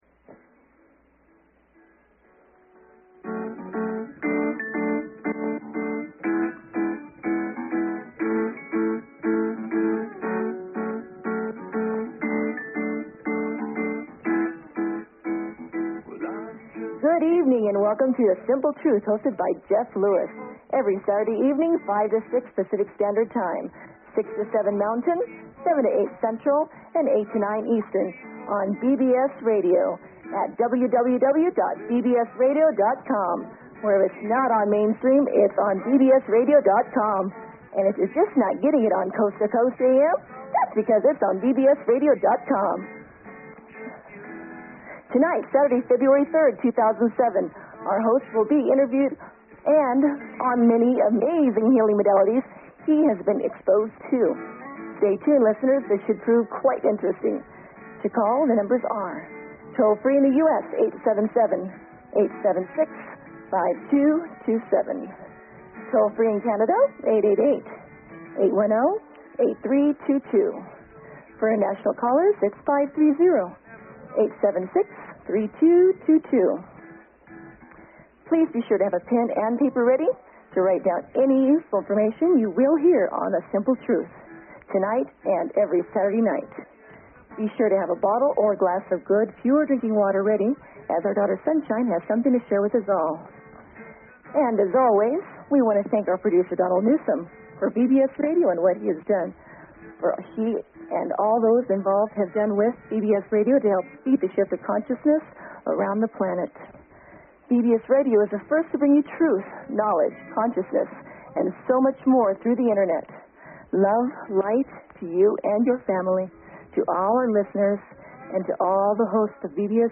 Talk Show Episode, Audio Podcast, The_Simple_Truth and Courtesy of BBS Radio on , show guests , about , categorized as